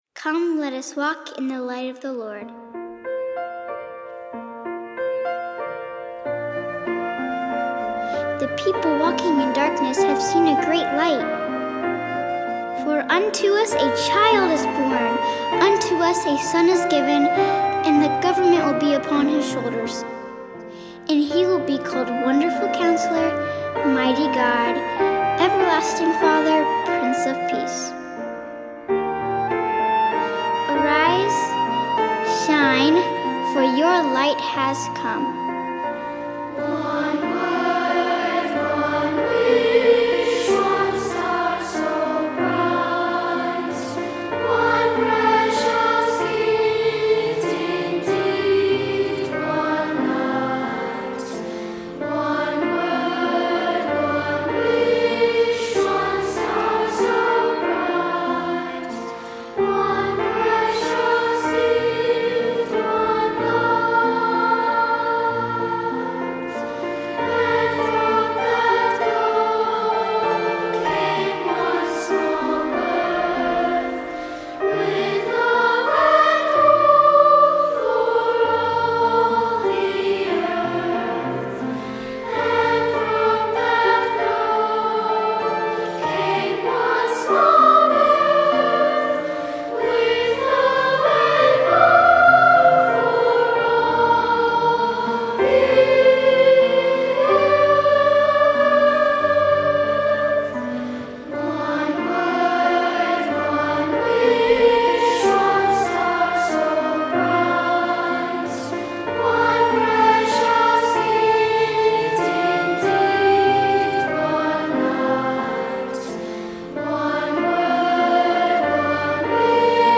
Voicing: Unison and Piano